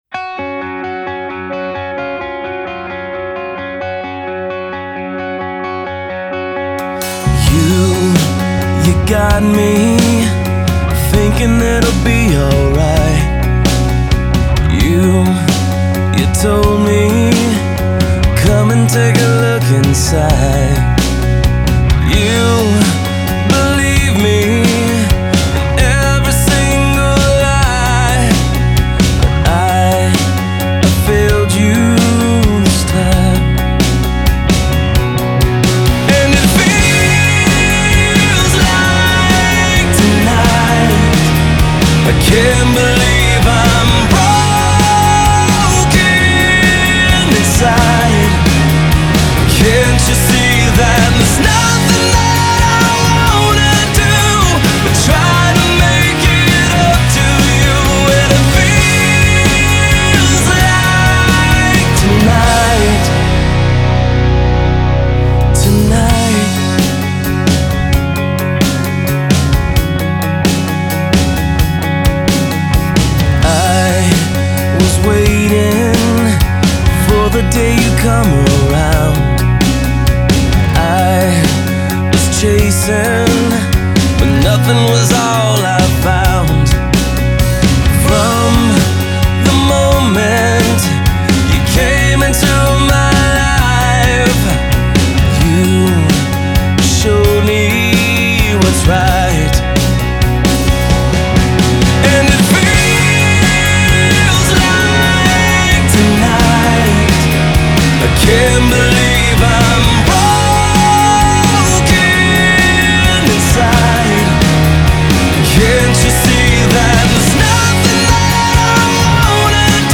Барабаны
Бас, гитары